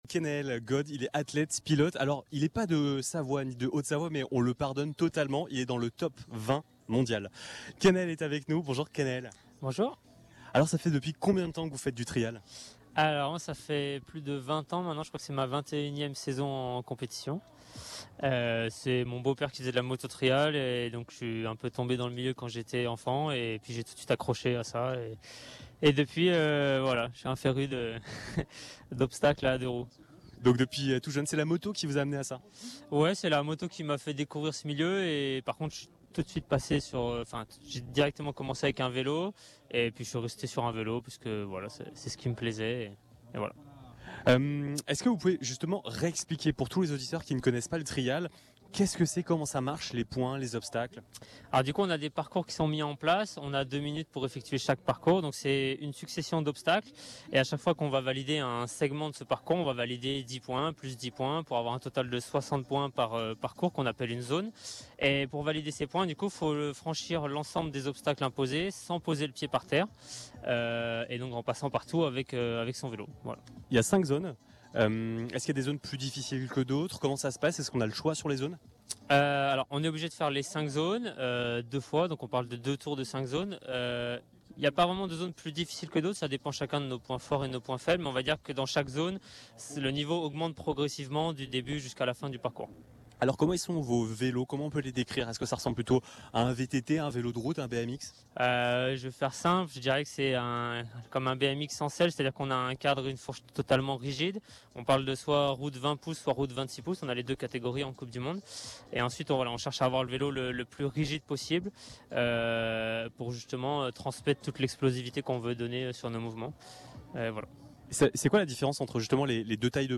À l’occasion de la Coupe du Monde de VTT Trial, nous étions en direct de Cordon pour une émission spéciale en direct du cœur de l’événement.
Interview